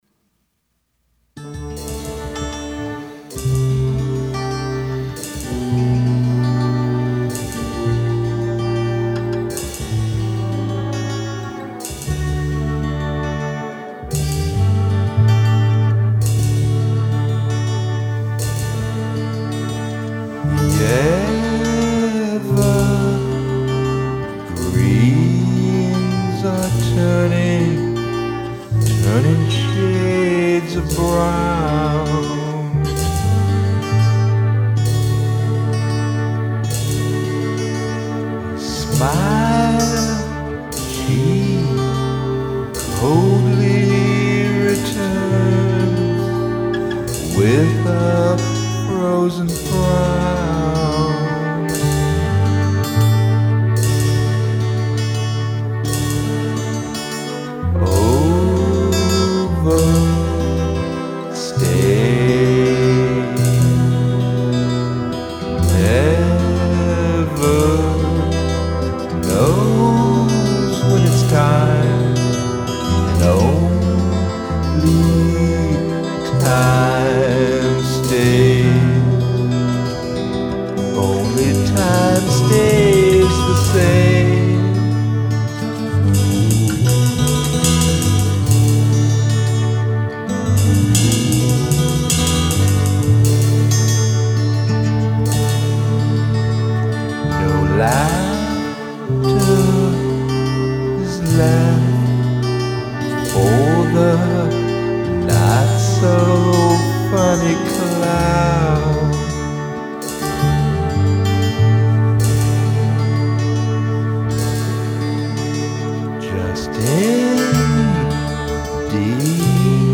Remixed and Remastered